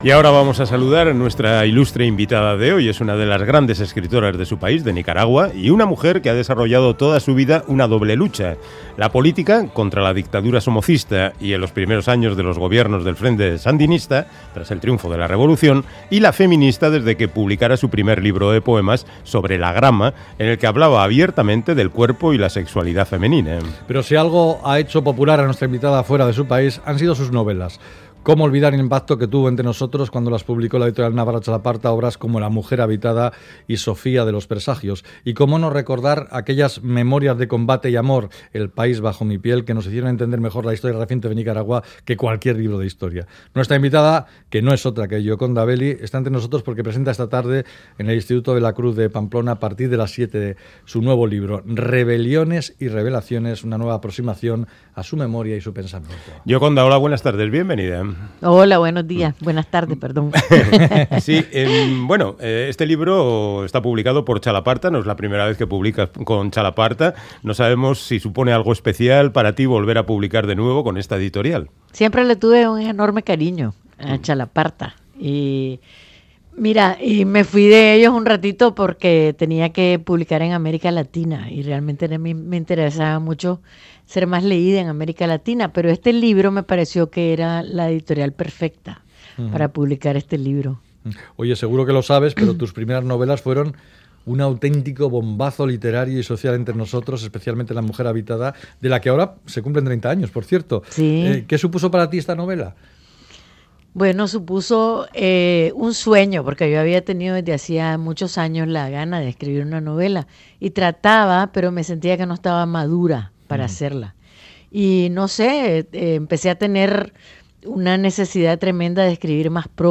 Audio: Hablamos con la nicaragüense Gioconda Belli de Rebeliones y Revelaciones